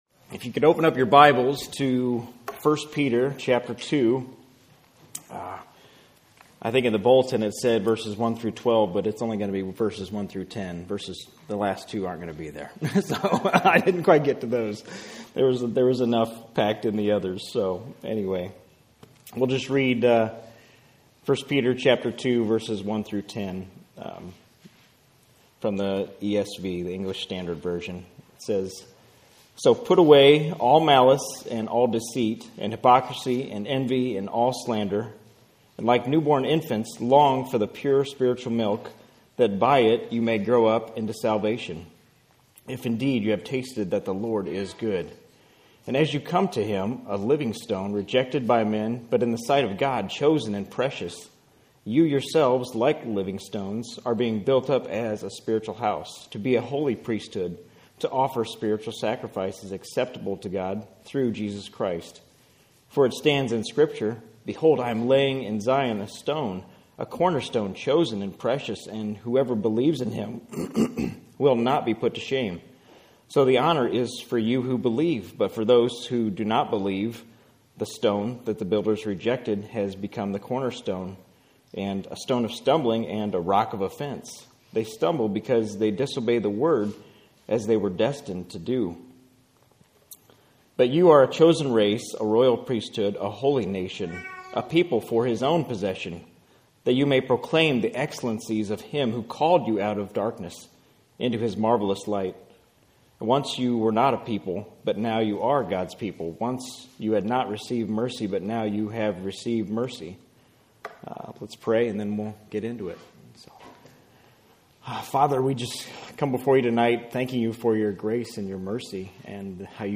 A teaching